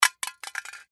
Descarga de Sonidos mp3 Gratis: juguete 6.
juguetes-juguete-13-.mp3